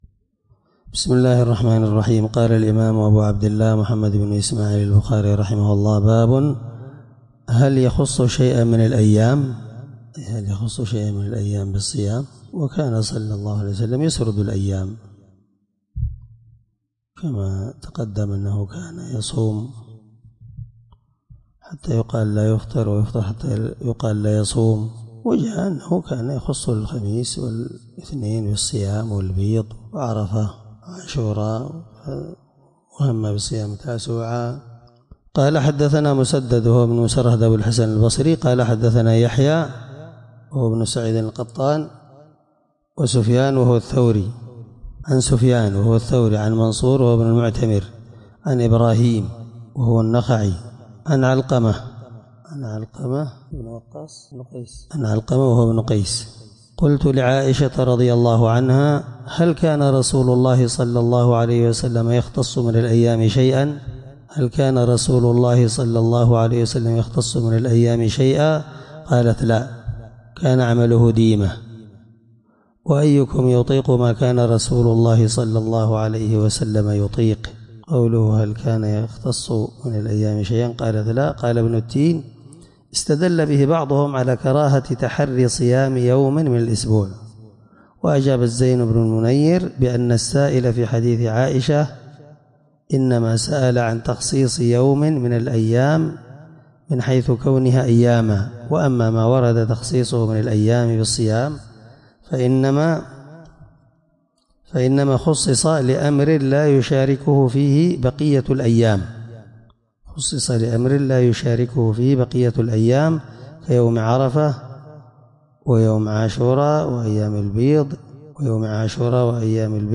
الدرس56 من شرح كتاب الصوم رقم(1987)من صحيح البخاري